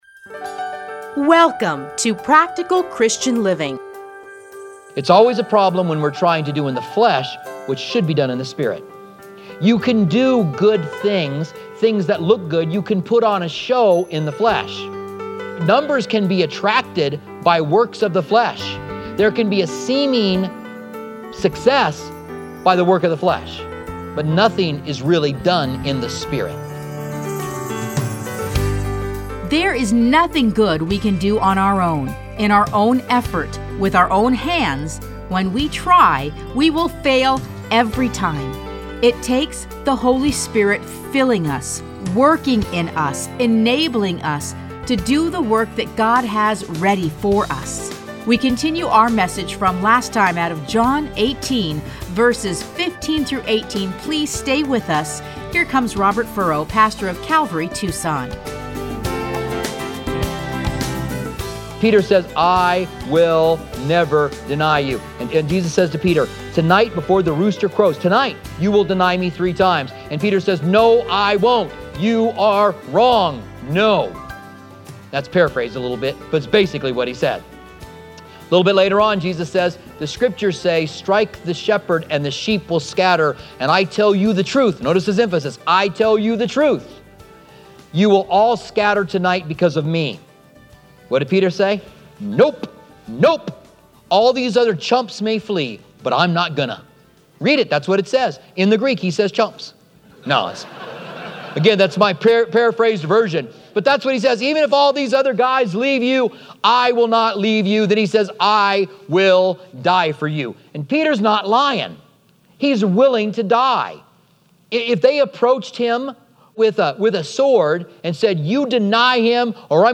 Listen to a teaching from John 18:15-27.